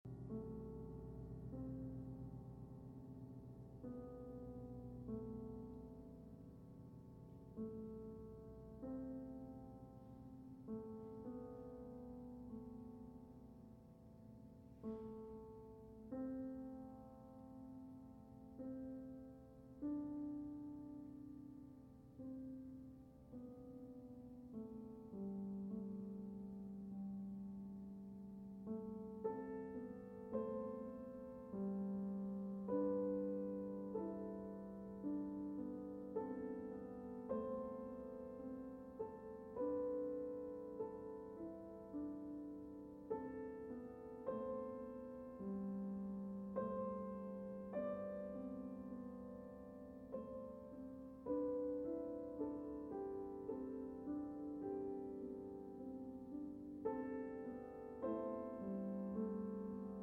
bassoon
Piano